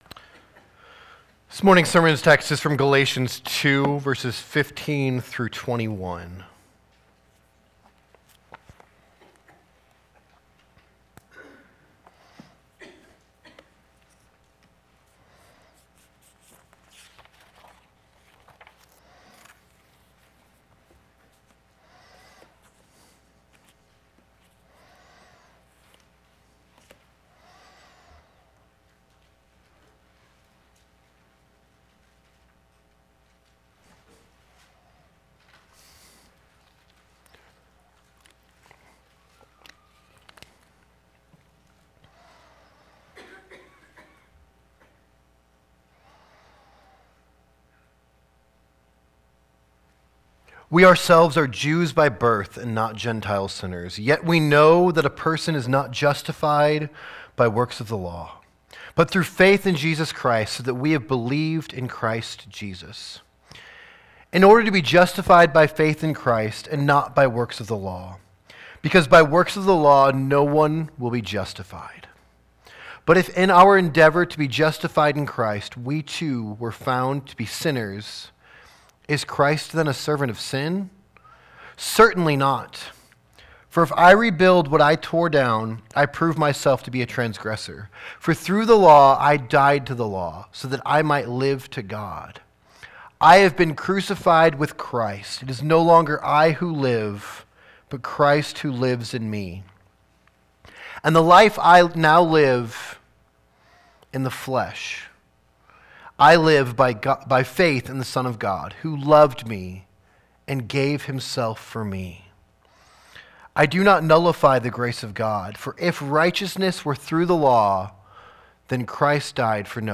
Sermons | Maranatha Baptist Church